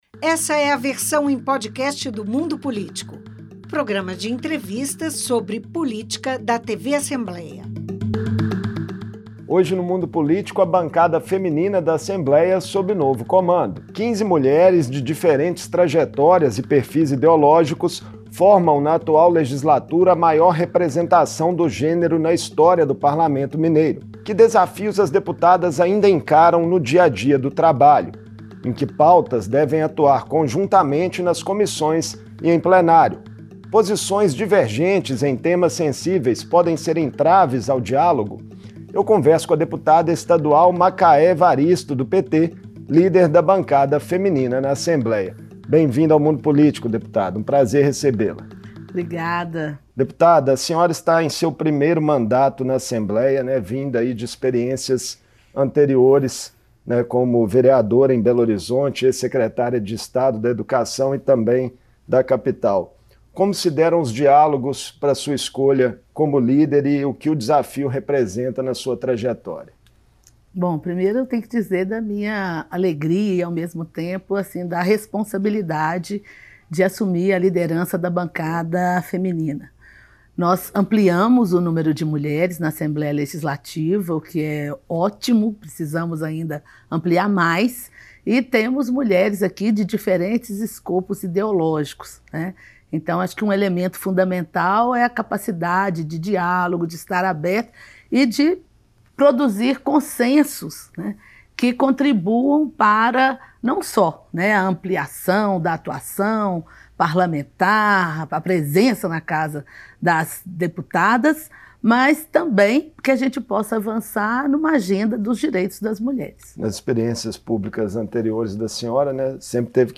Assume o comando do grupo de 15 parlamentares na Assembleia Macaé Evaristo (PT), deputada em primeiro mandato, ex-vereadora, ex-secretária de Estado e experiente educadora. Em entrevista